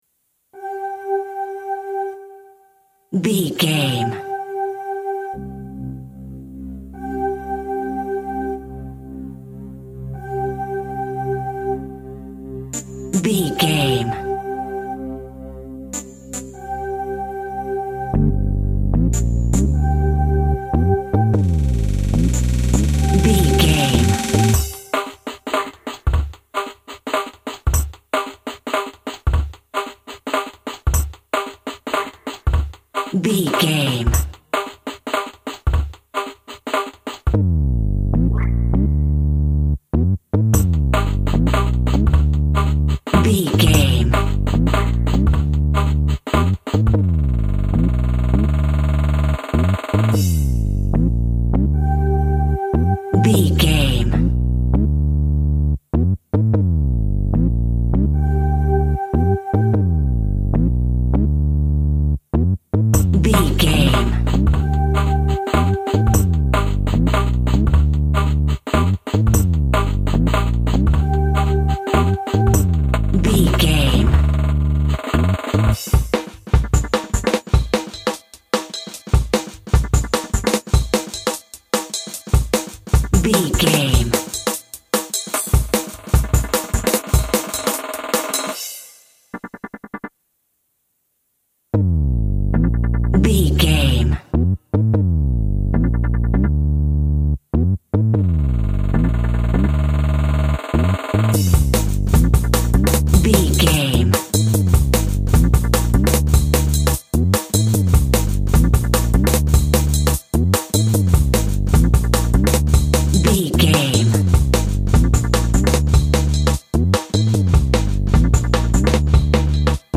Drum & Bass Jungle.
Atonal
Fast
futuristic
hypnotic
industrial
frantic
aggressive
synthesiser
drum machine
synth lead
synth bass